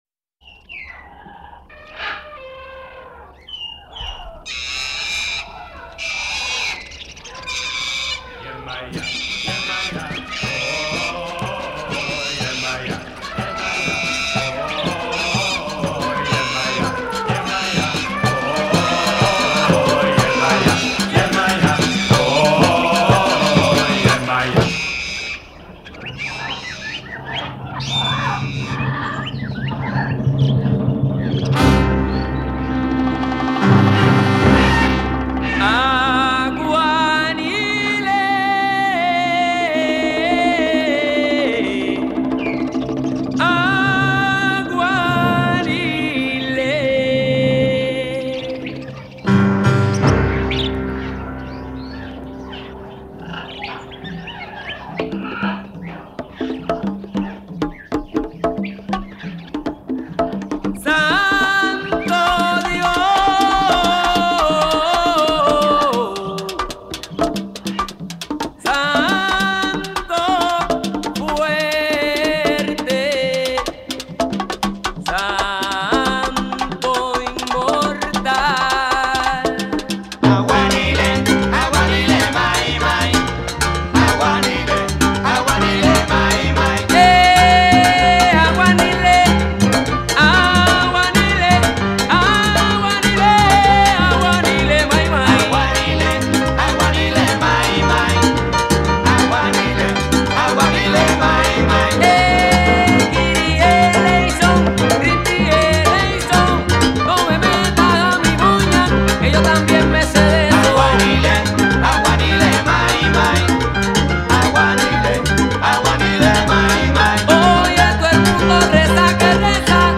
это сальса-молитва, почти сальса-госпел.